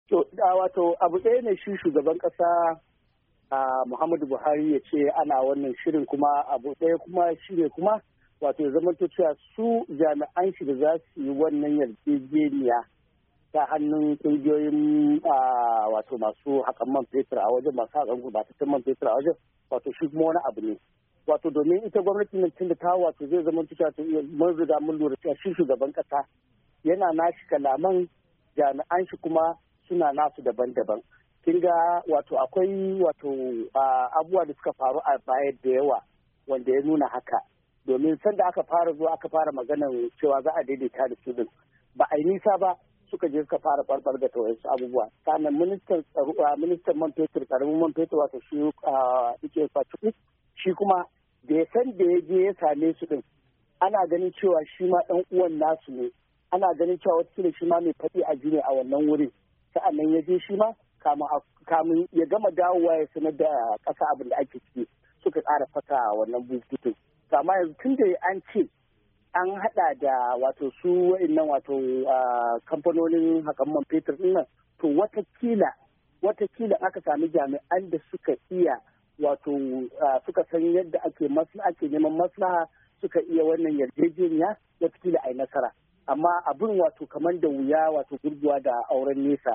mai fashin baki ne kan harkokin tsaro